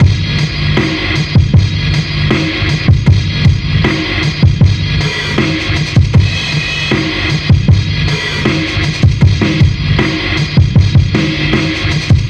Track 07 - Drum Break 04.wav